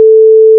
**🔊 SFX PLACEHOLDERS (23 WAV - 1.5MB):**
Building (5): chest, door_open, door_close, hammer, repair
**⚠  NOTE:** Music/SFX are PLACEHOLDERS (simple tones)
door_open.wav